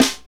Index of /90_sSampleCDs/Northstar - Drumscapes Roland/KIT_Hip-Hop Kits/KIT_Rap Kit 3 x
SNR H H S04L.wav